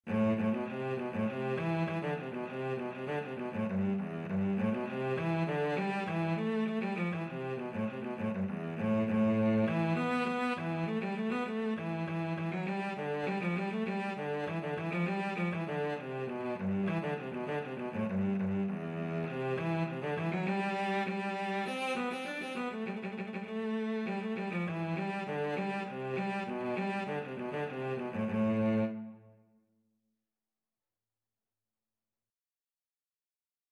Traditional Turlough O Carolan Mrs. Anne Macdermott Roe Cello version
Free Sheet music for Cello
2/4 (View more 2/4 Music)
E3-D5
A minor (Sounding Pitch) (View more A minor Music for Cello )
Cello  (View more Intermediate Cello Music)
Traditional (View more Traditional Cello Music)